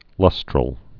(lŭstrəl)